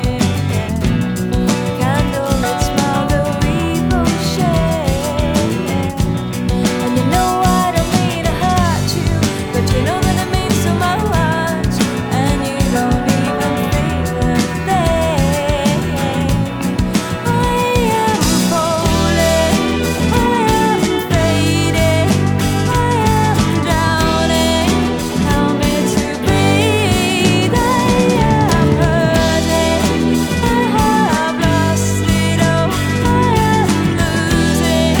Жанр: Поп / Инди